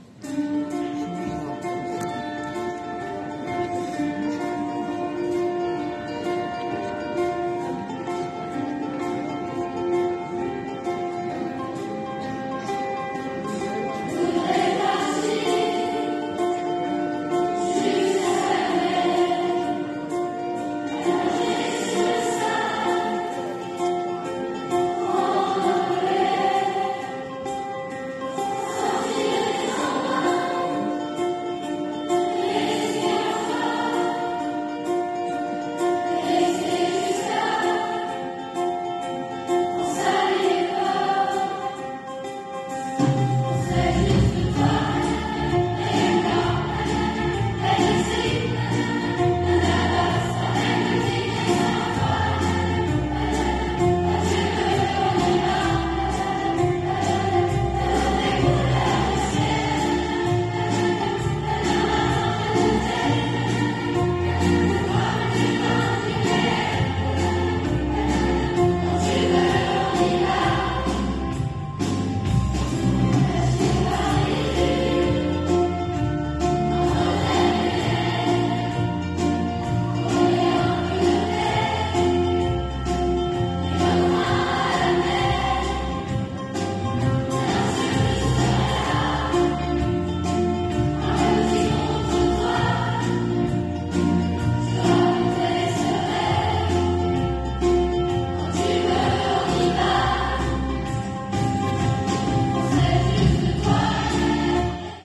La Chorale du collège en mode "Juke box" pour le Festival du Chant choral 2024 - COLLEGE RAYMOND QUENEAU
La chorale du collège, composée cette année de 60 choristes, a participé à son 2ème Festival Académique du Chant Choral au Carré des Docks.